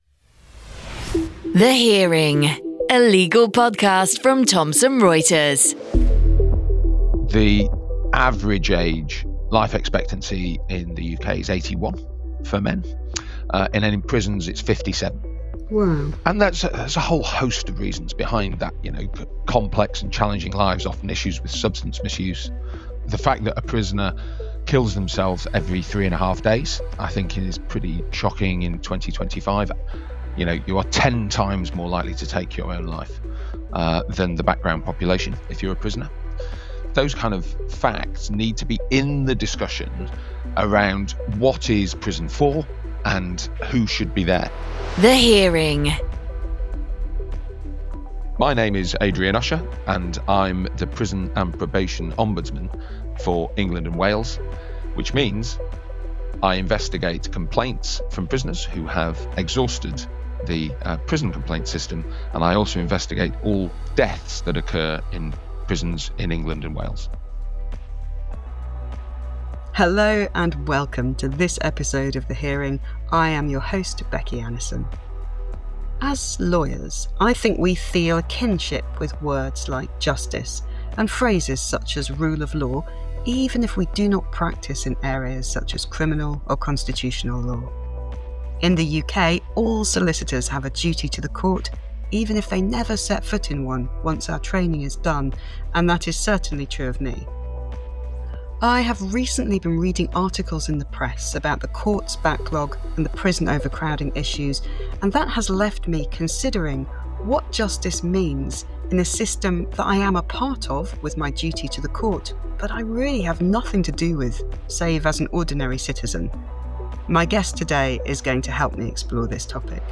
Her guest, Adrian Usher, the UK’s Prisons and Probation Ombudsman, explains how delayed trials are creating a bottleneck in prisons, with some Crown Court cases now being scheduled as far ahead as 2028, and he outlines the human…